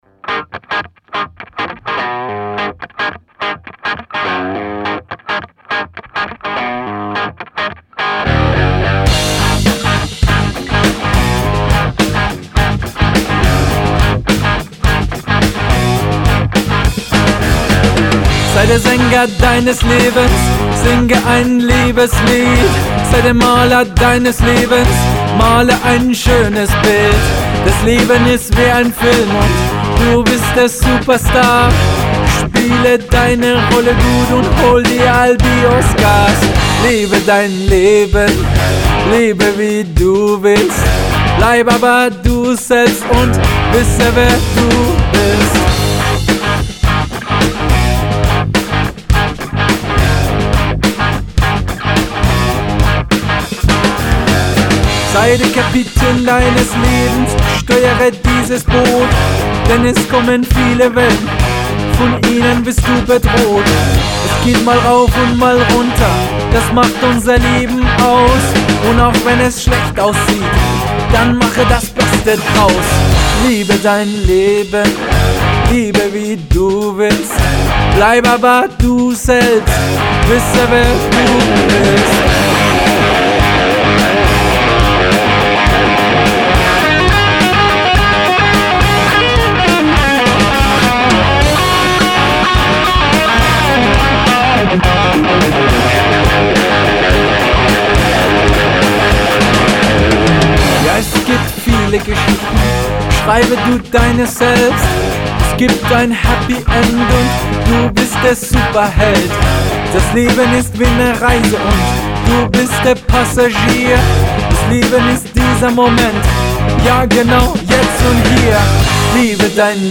Rockband